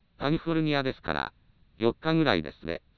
以下に本システムで作成された合成音声を状態継続長の符号化手法別にあげる